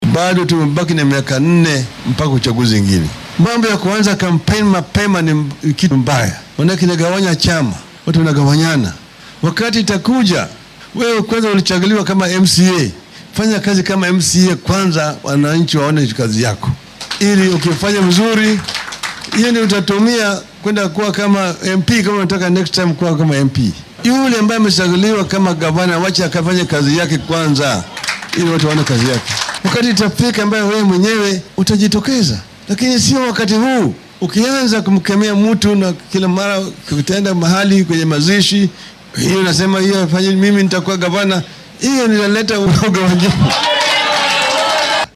Arrintan ayuu ka sheegay ismaamulka Kilifi ee gobolka Xeebta oo uu ka daahfuray diiwaangelinta xubnaha cusub ee xisbiga ODM ee uu hoggaamiyo.